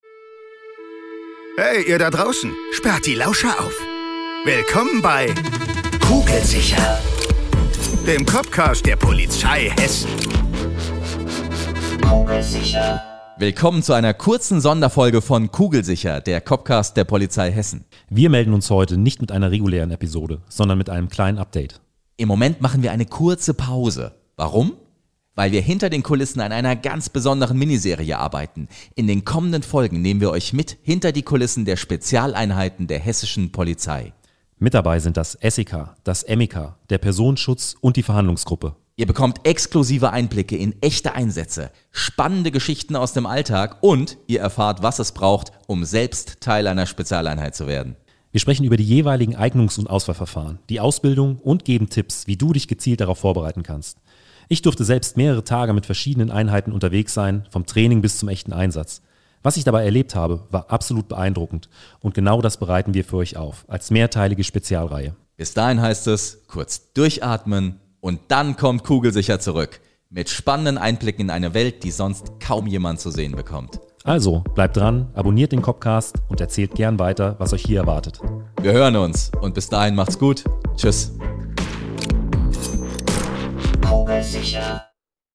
Bei uns dreht sich alles um den echten Polizeialltag, mit echten Cops am Mikro.